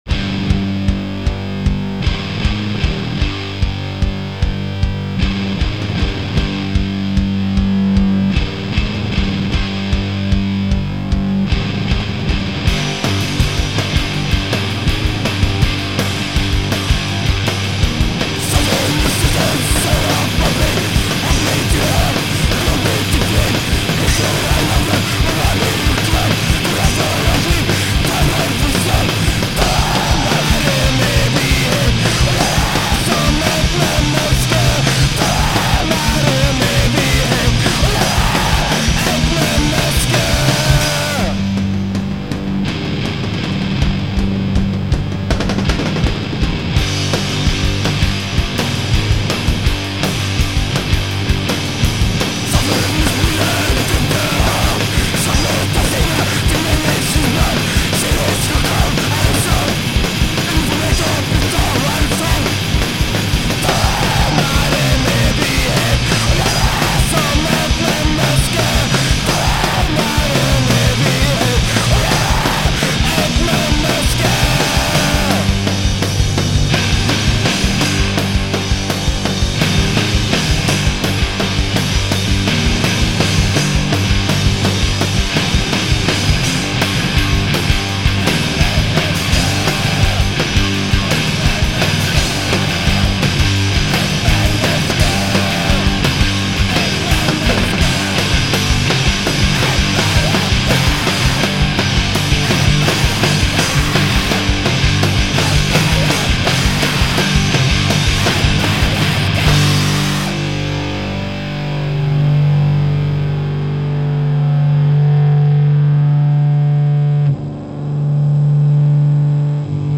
live fast die young punkrock